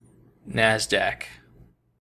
The Nasdaq Stock Market (/ˈnæzdæk/
En-us-NASDAQ.ogg.mp3